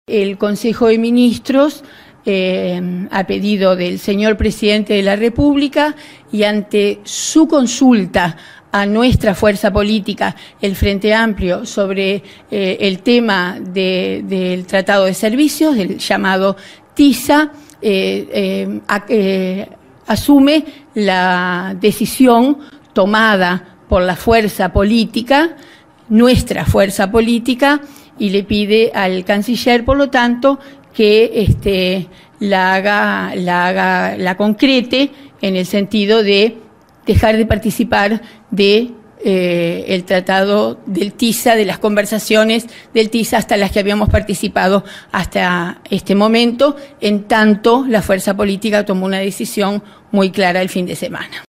La ministra de Turismo lilium Kechichián aseguró en conferencia de prensa tras el Consejo de Ministros que Vázquez "asume la resolución" de salir de las negociaciones del TISA.
Escuche a la ministra